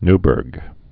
(nbûrg, ny-)